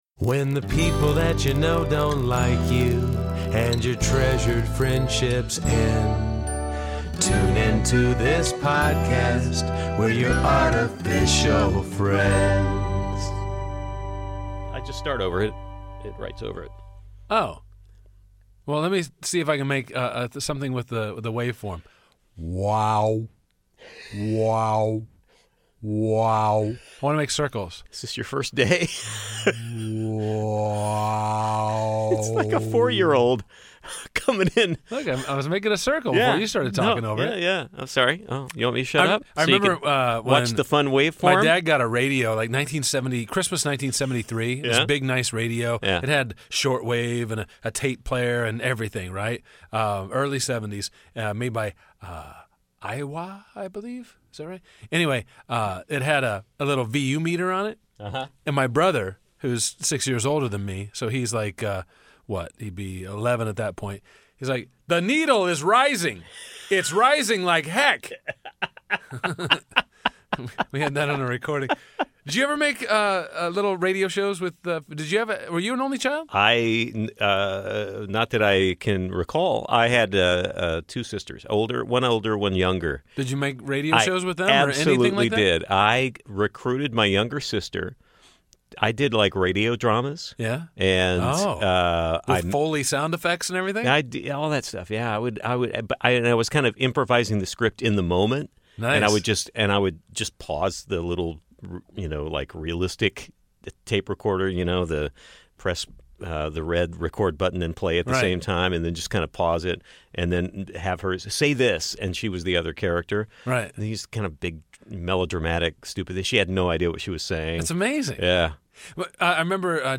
Do you hear the static in the first five seconds of this podcast?